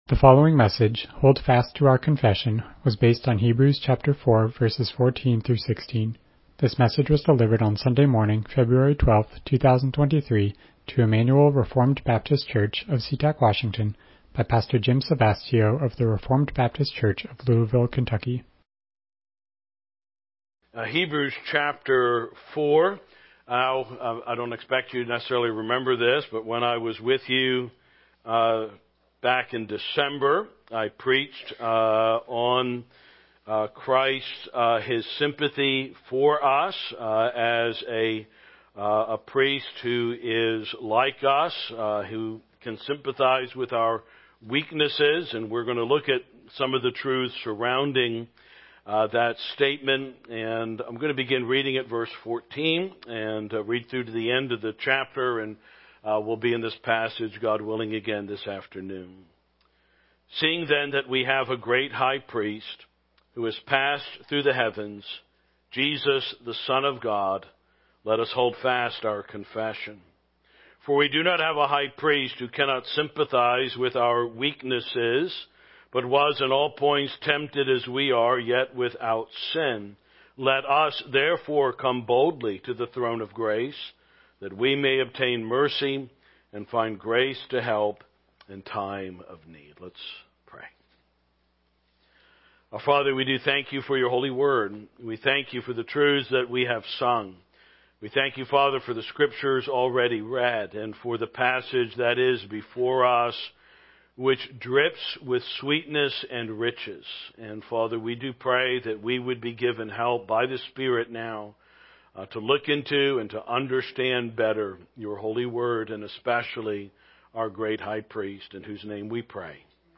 Passage: Hebrews 4:14-16 Service Type: Morning Worship « What Would You Do If You Knew the World Was Ending?